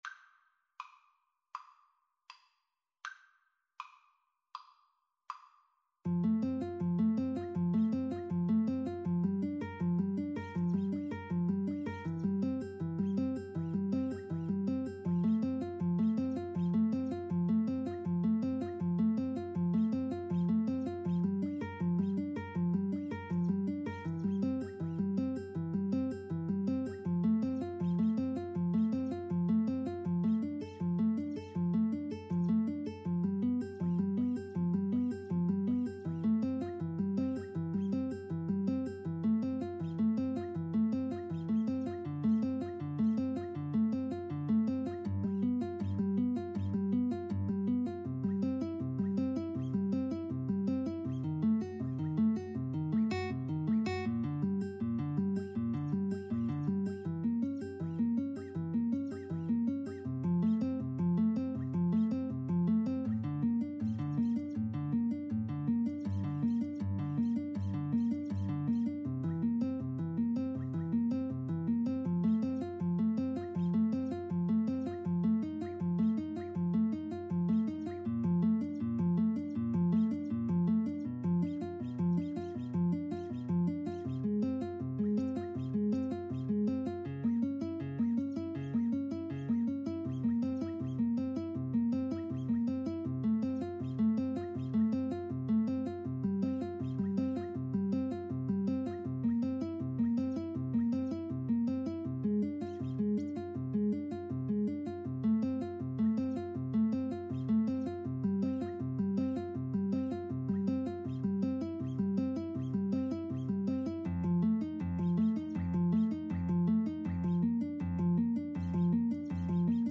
• Unlimited playalong tracks
Arrangement for Guitar Duet
Classical (View more Classical Guitar Duet Music)